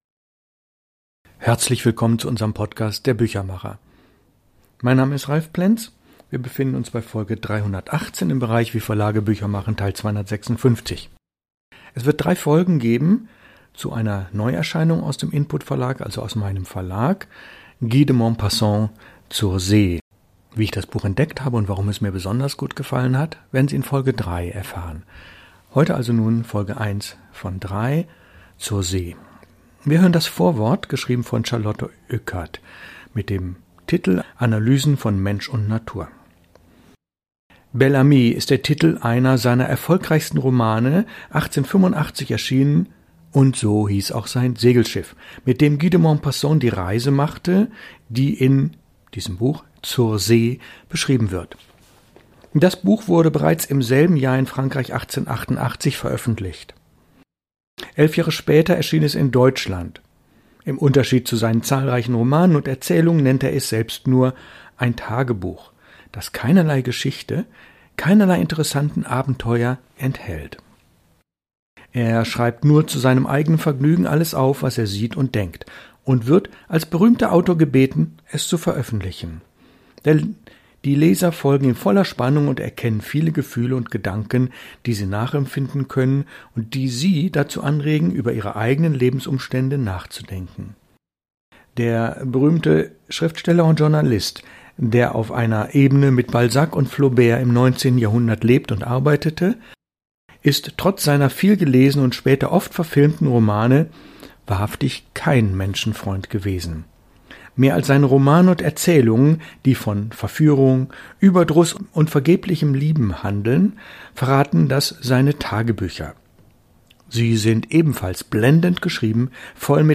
1. Lesung aus dem Buch von Guy de Maupassant (1888): „Zur See“, Folge 1 von 3